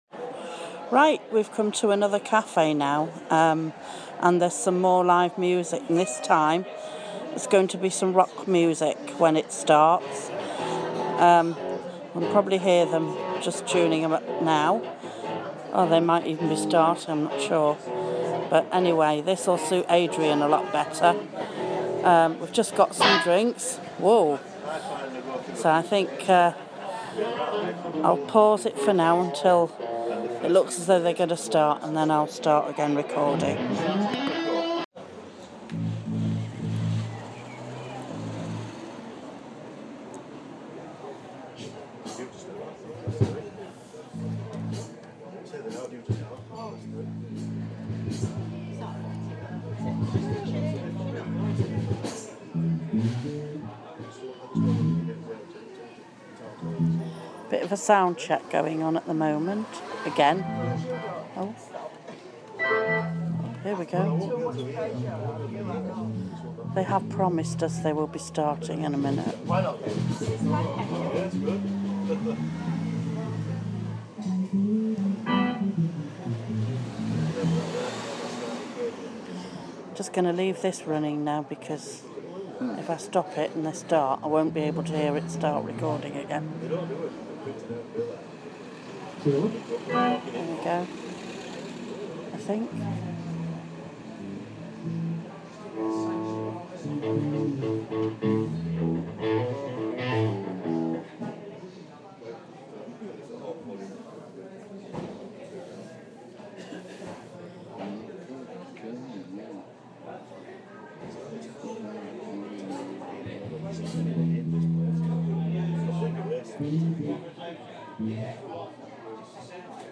More Live music